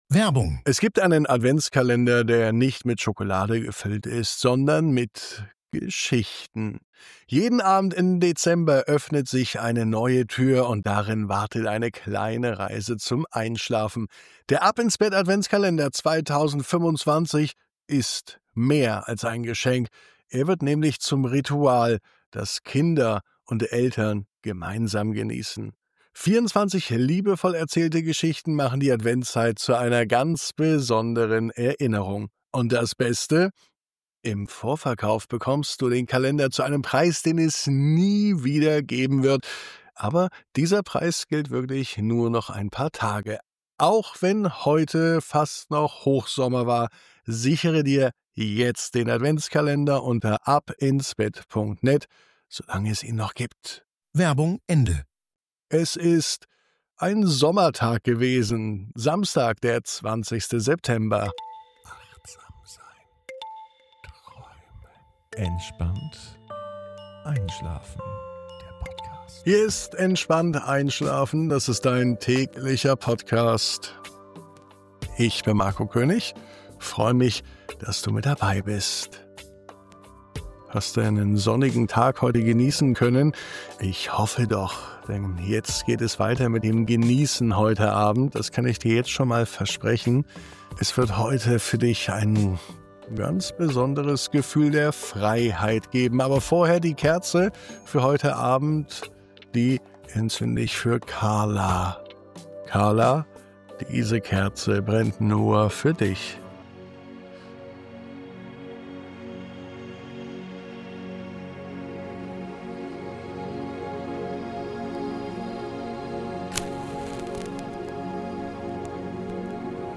In dieser Episode begleiten dich sanfte Worte, beruhigende Bilder und eine achtsame Traumreise auf einen Weg, der dich ganz behutsam zurück zu dir bringt. Du wanderst durch weite Landschaften, lässt Sorgen hinter dir und findest einen Ort in dir, an dem nichts erwartet wird – außer deinem Sein.